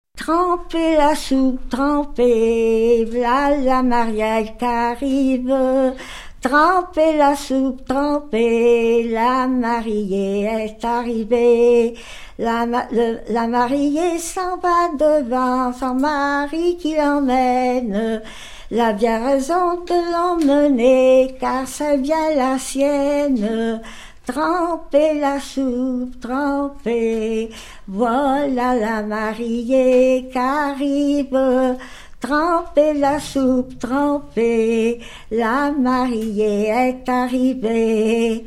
Genre laisse
Catégorie Pièce musicale inédite